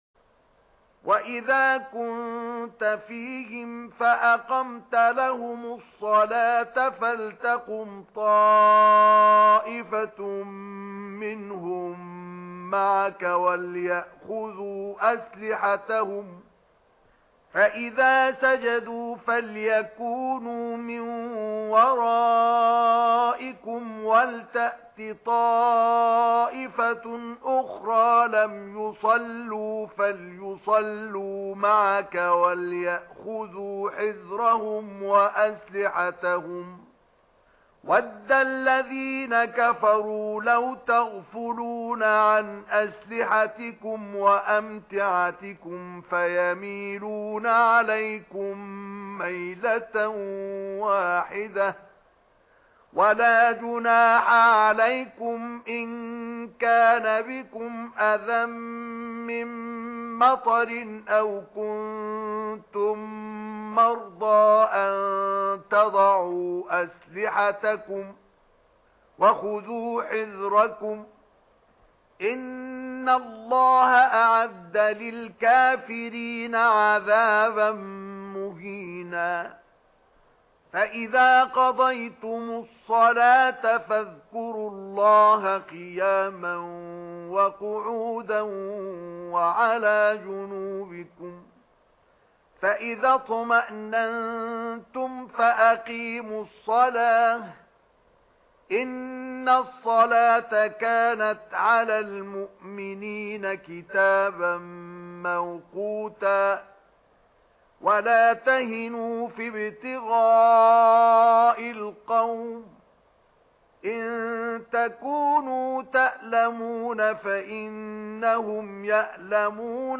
تلاوت ترتیل صفحه ۹۵ قرآن باصدای مصطفی اسماعیل+صوت و متن آیات
در ادامه تلاوت ترتیل صفحه ۹۵ قرآن کریم شامل آیات ۱۰۱ تا ۱۰۵ سوره مبارکه «نساء» باصدای مرحوم مصطفی اسماعیل تقدیم می‌شود.